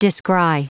Prononciation du mot descry en anglais (fichier audio)